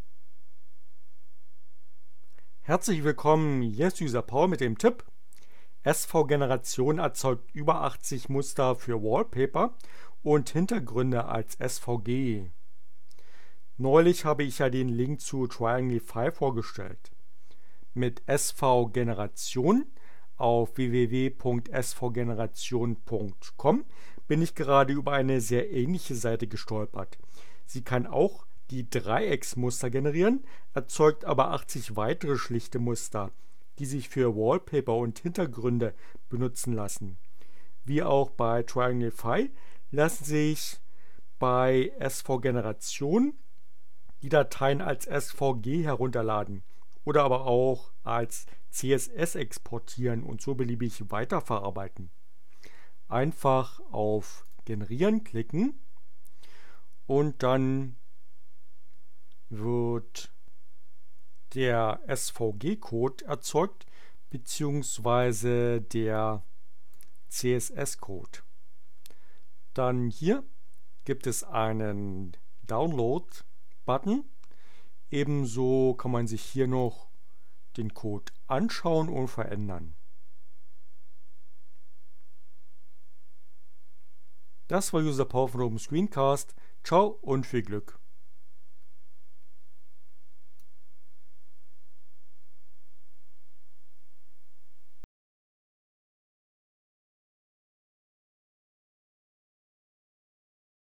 Tags: CC by, Linux, Neueinsteiger, ohne Musik, screencast, SVG, Wallpaper, SVGeneration, Web